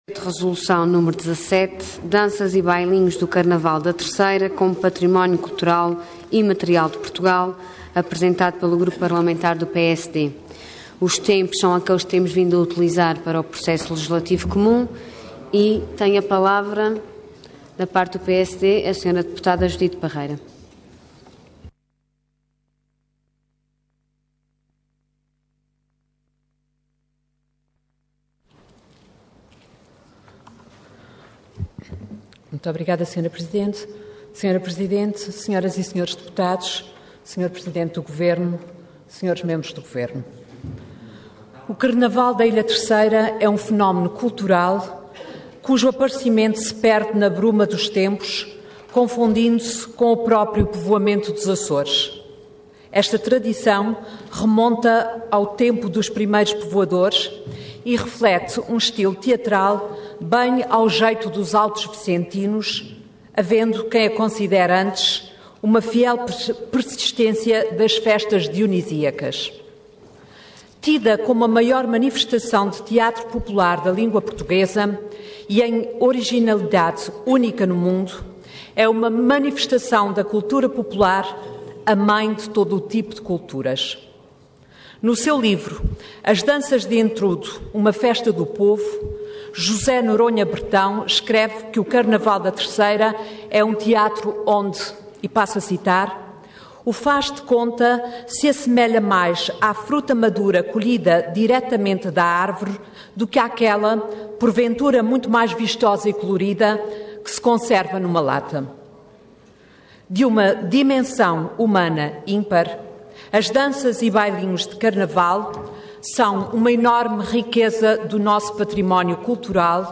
Intervenção Projeto de Resolução Orador Judite Parreira Cargo Deputada Entidade PSD